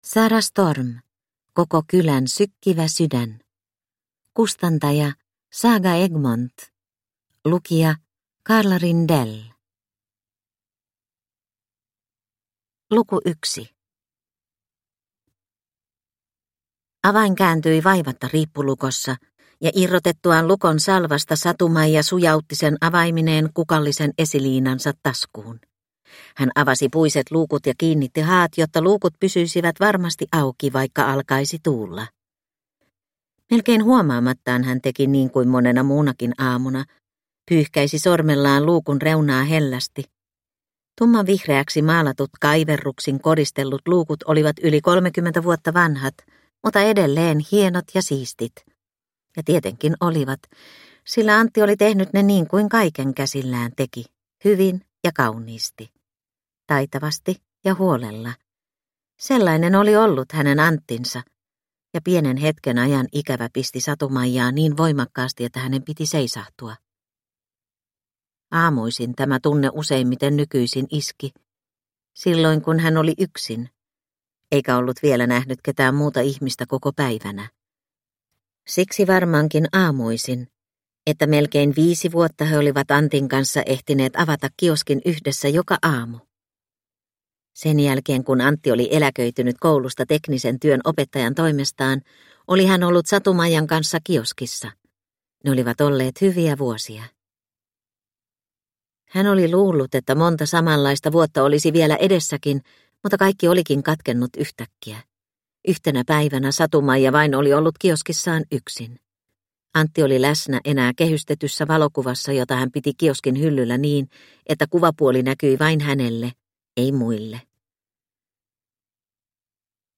Koko kylän sykkivä sydän / Ljudbok